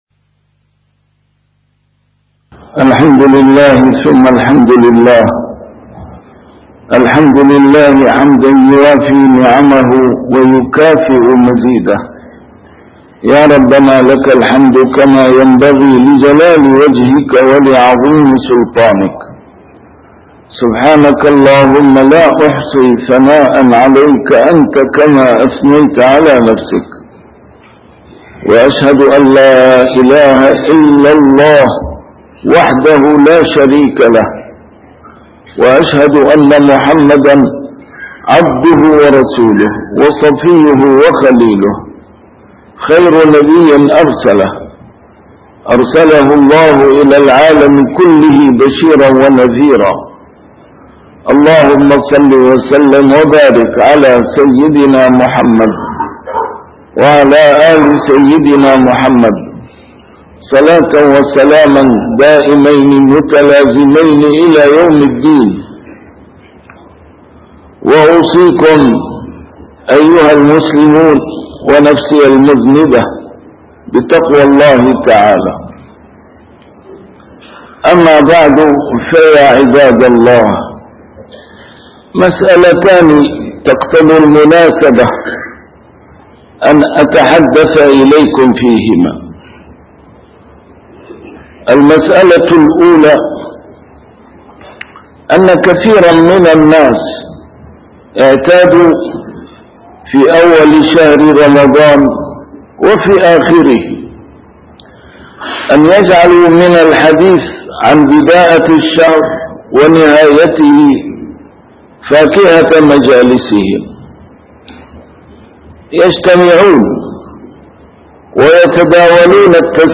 A MARTYR SCHOLAR: IMAM MUHAMMAD SAEED RAMADAN AL-BOUTI - الخطب - مسألتان تقتضي المناسبة الحديث عنهما (ثبوت الشهر وزكاة الفطر)